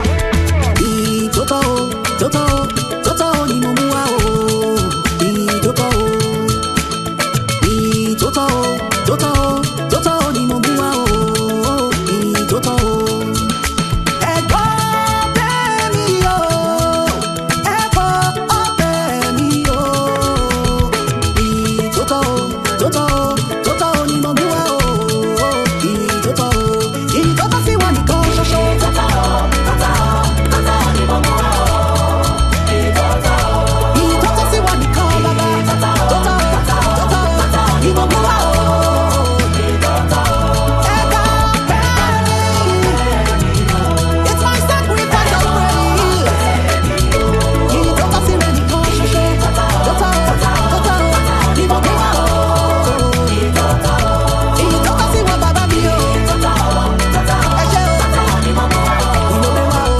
joyful song